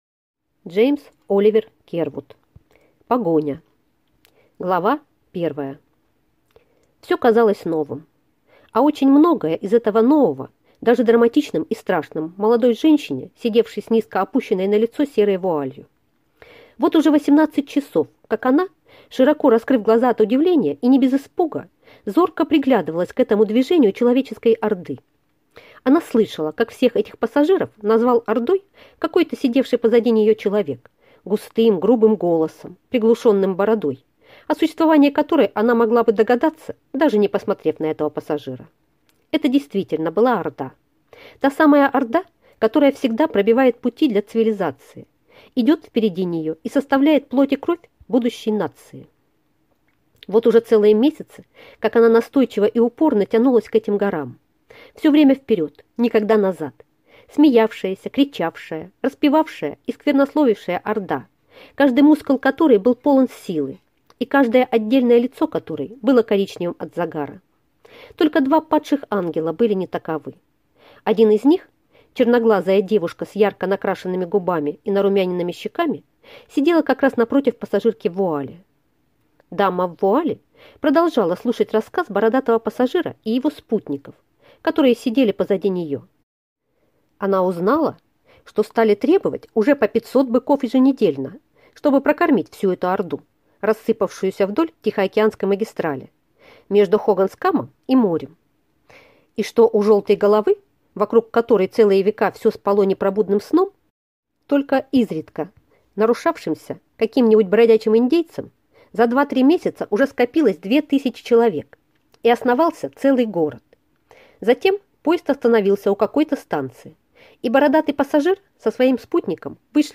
Аудиокнига Погоня | Библиотека аудиокниг
Прослушать и бесплатно скачать фрагмент аудиокниги